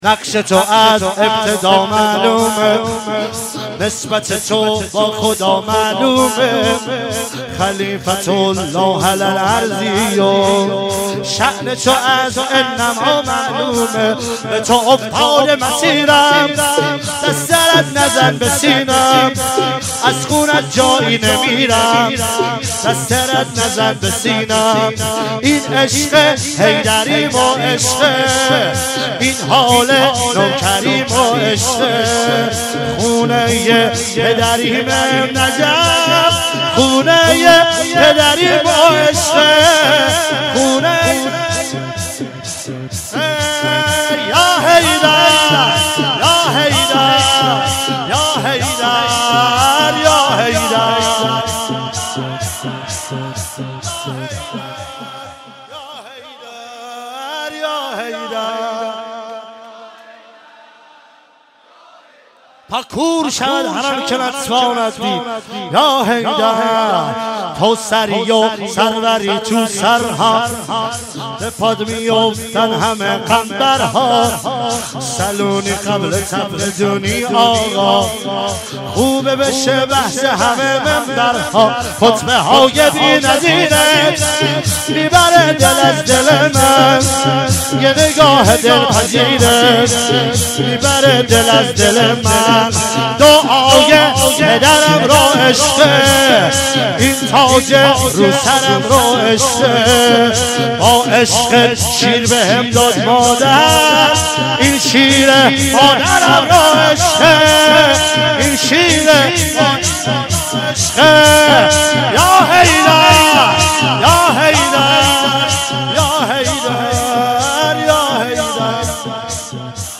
مراسم فاطمیه اول ۹۶
شور